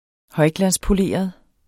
Udtale [ ˈhʌjglanspoˌleˀʌð ]